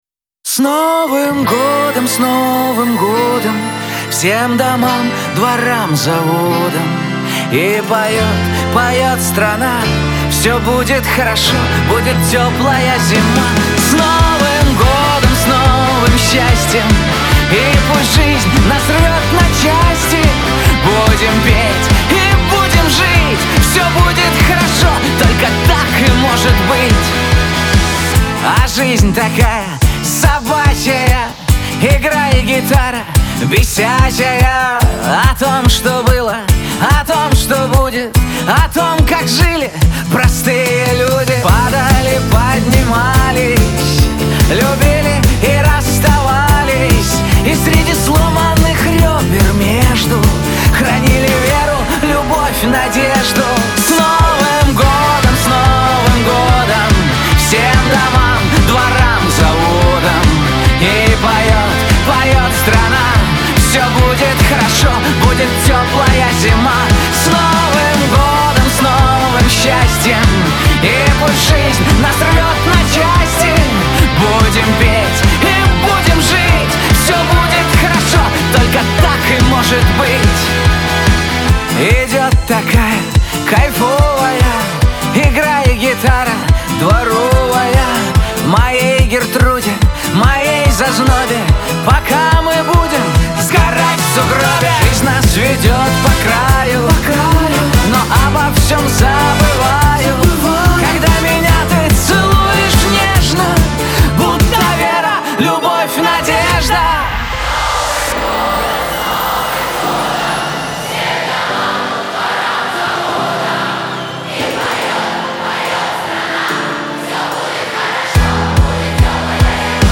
эстрада , диско
pop
дуэт